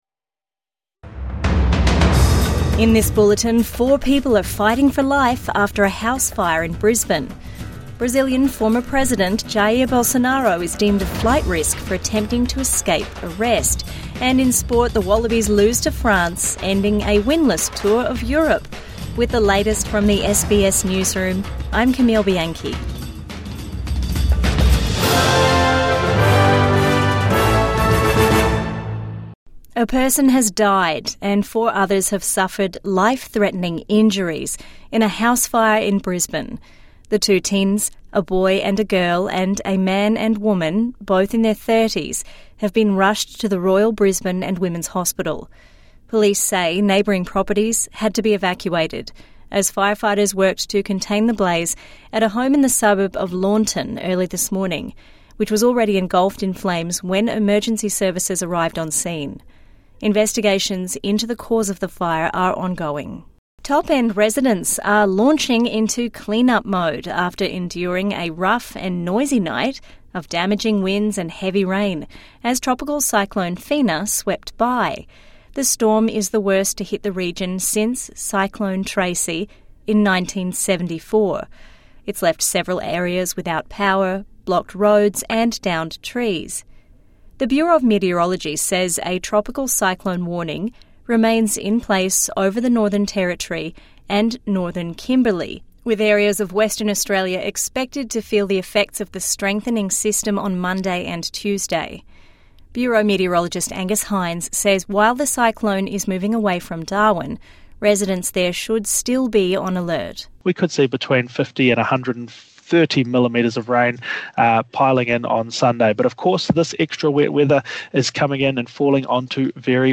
Investigation into fatal Brisbane house fire | Midday News Bulletin 23 November 2025